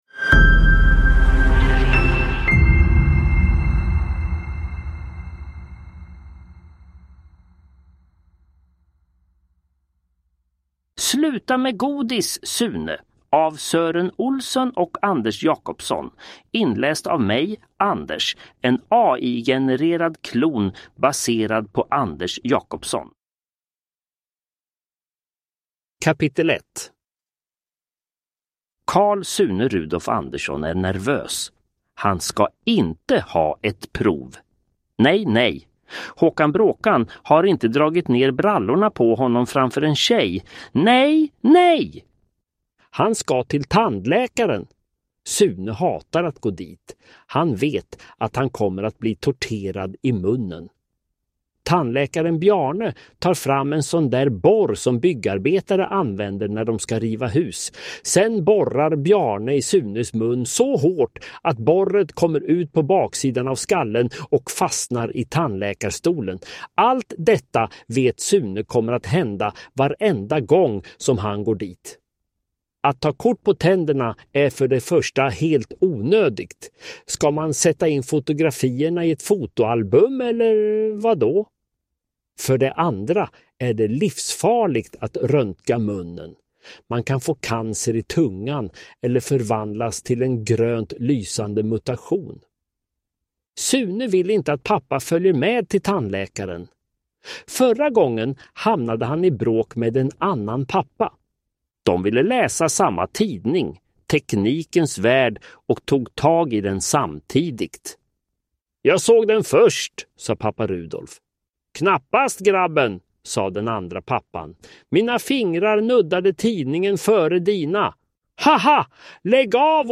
Sluta med godis, Sune – Ljudbok
Uppläsare: Anders Jacobsson AI